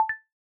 macOSsystemsounds
Text-Message-Acknowledgement-ThumbsUp.mp3